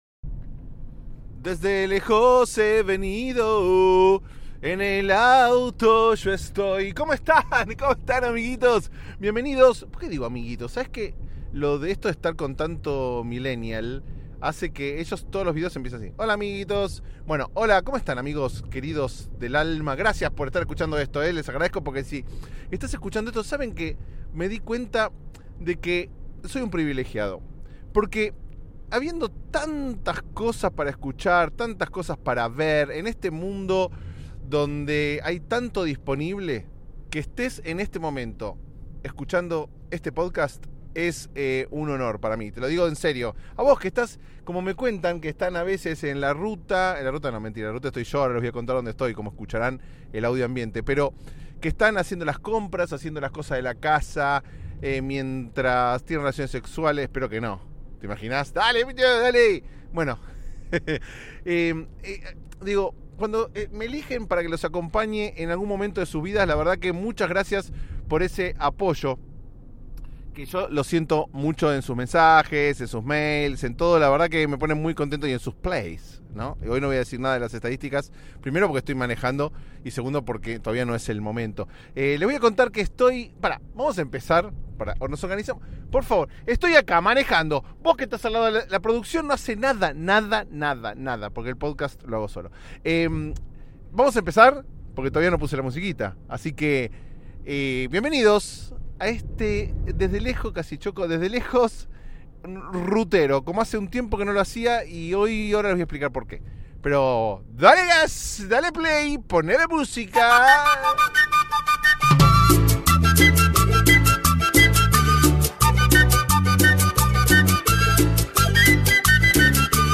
Un episodio como los de antes: en la ruta, manejando por California, acompañado por ustedes.